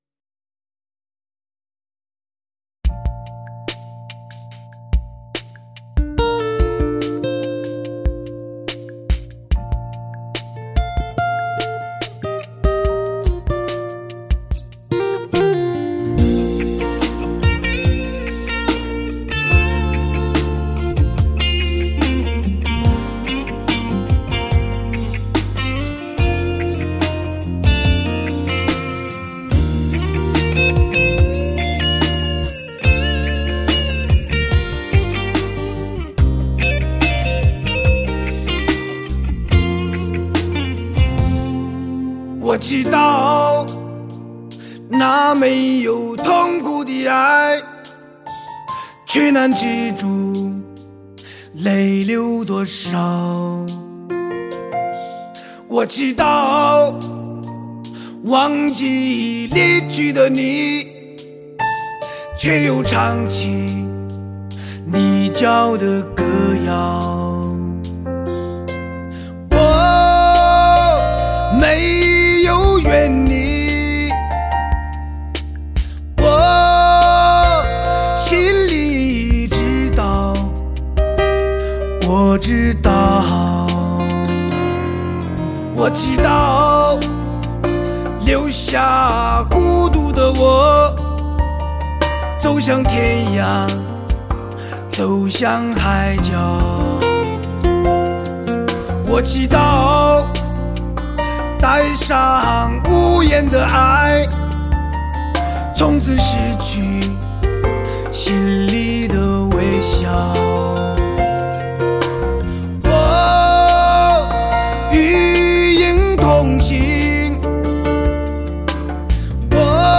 强磁新声代的顶级演绎，让人一秒钟爱上的震憾男声！